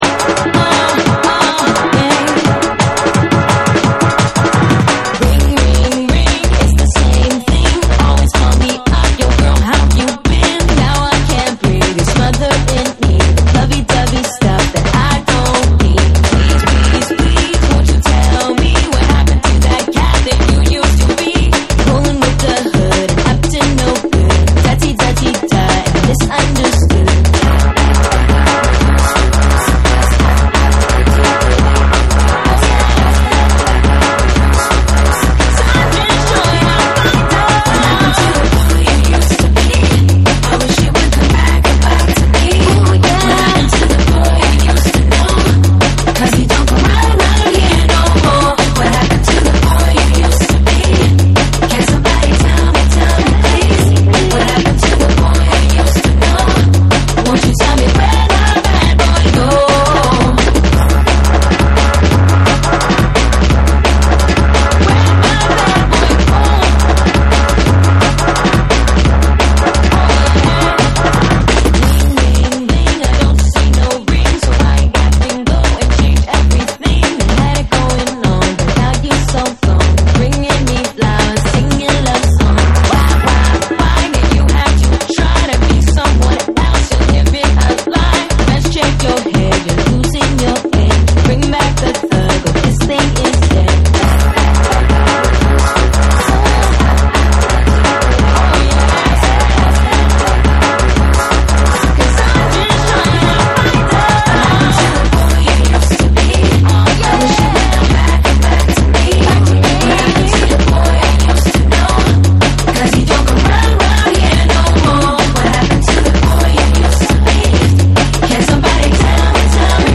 ダンスホールとジャングルが交わるトラックとキャッチーなラガMCが一体となりテンションも高まるジャングル・ナンバー